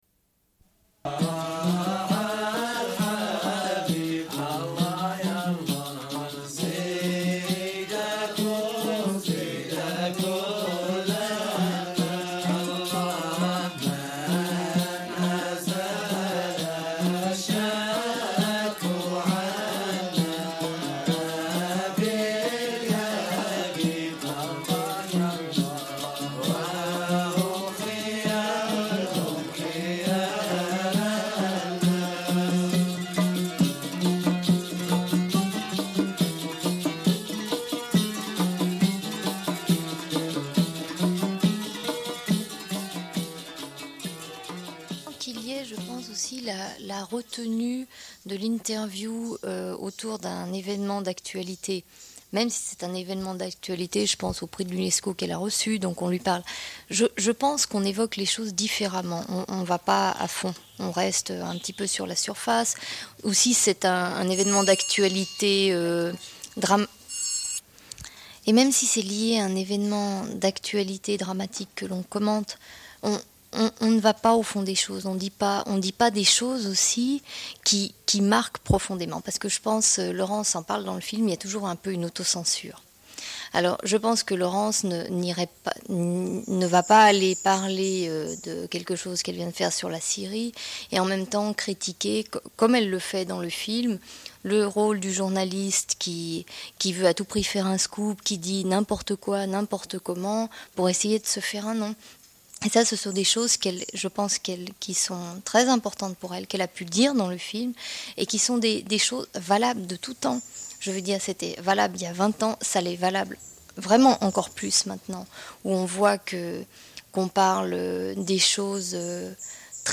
Une cassette audio, face B00:28:59
Diffusion d'extraits enregistrés du film.